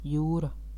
Ääntäminen
France (Paris): IPA: [yn mɛʁ]